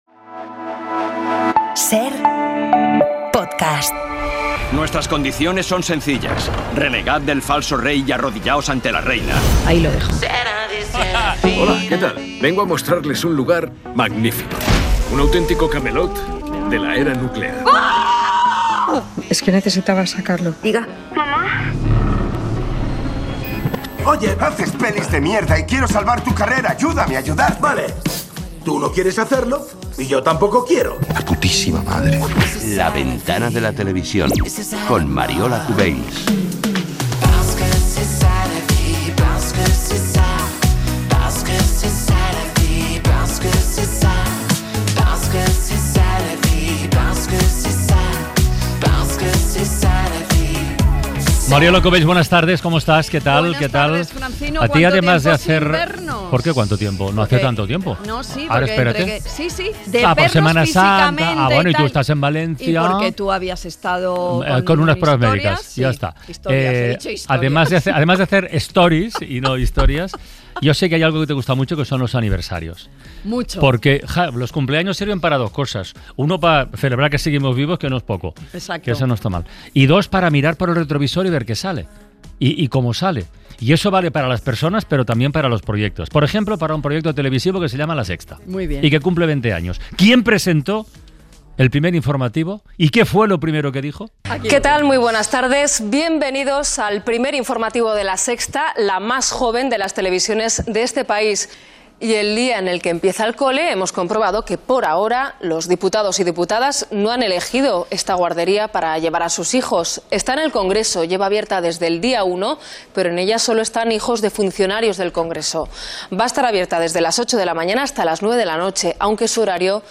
La Ventana de la Tele | Helena Resano 30:56 SER Podcast Mariola Cubells invita a 'La Ventana de la Tele' a la periodista y presentadora Helena Resano.